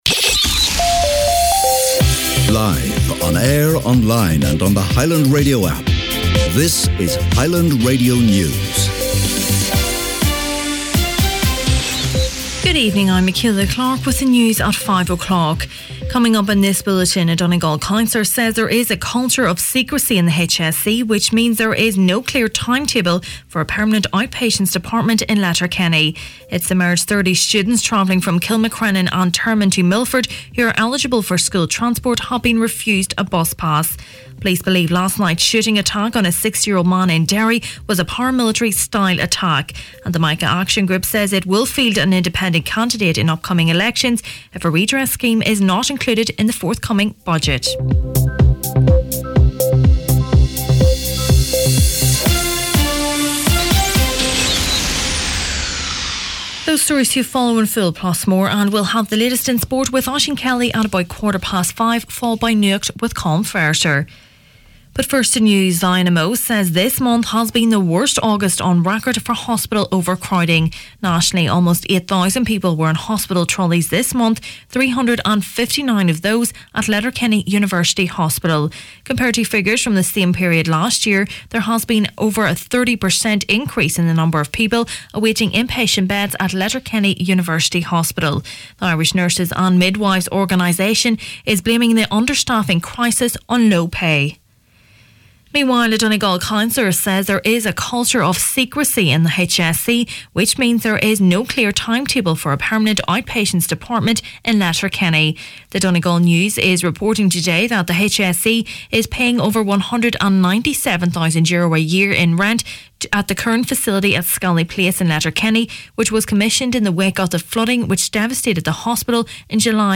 Main Evening News, Sport, Obituaries and Nuacht Friday August 31st